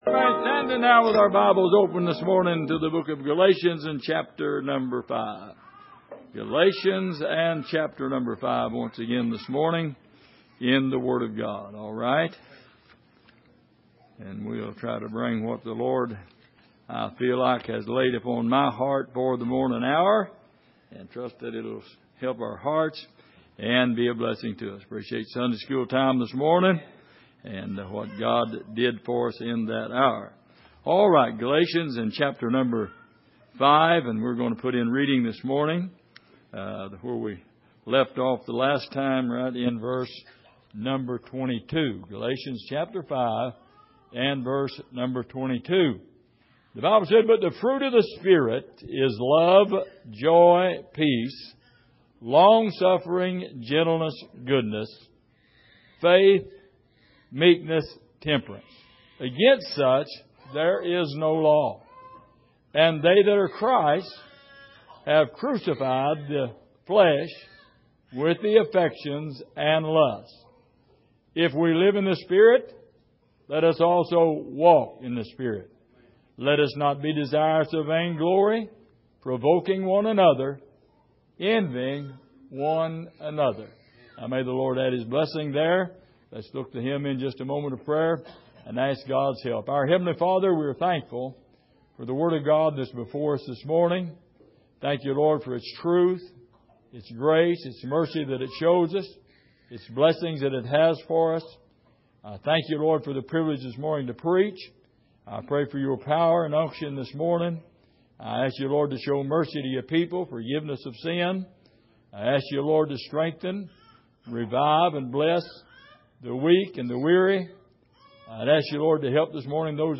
Miscellaneous Passage: Galatians 5:22-26 Service: Sunday Morning Our Fellow Man « The Constant Battle